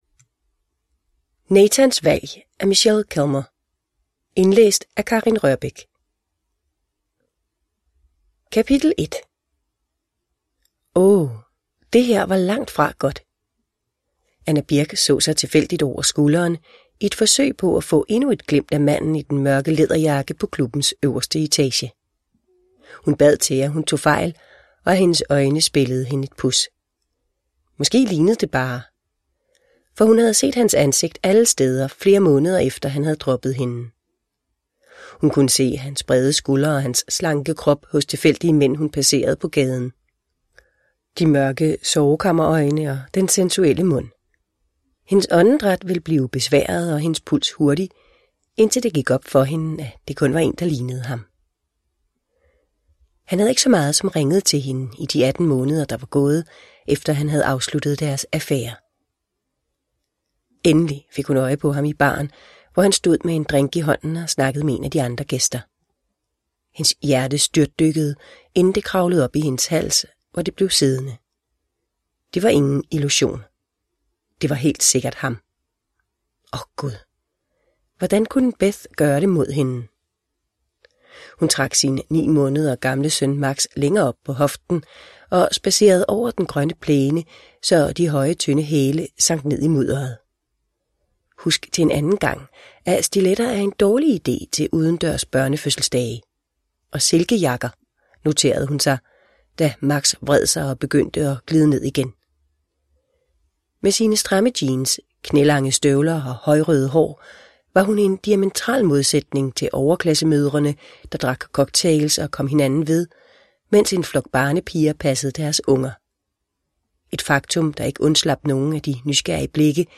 Nathans valg – Ljudbok – Laddas ner